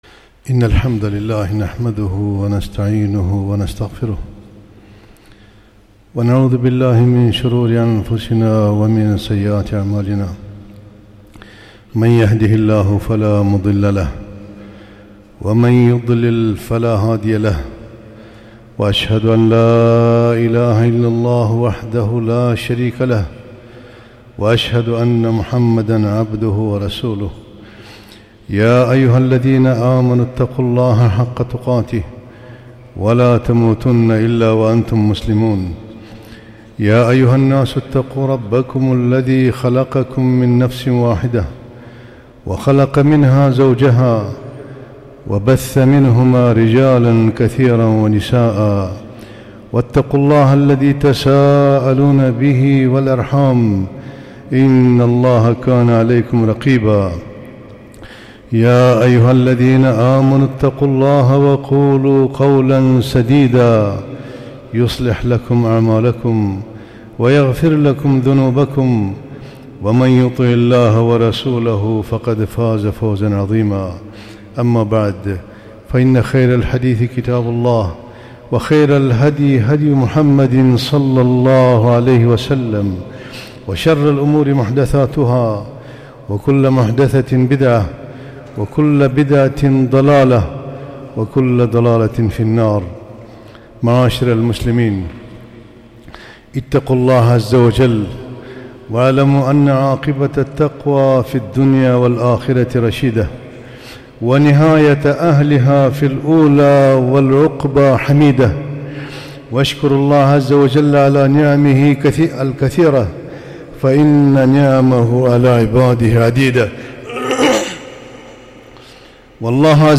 خطبة - فوائد النخلة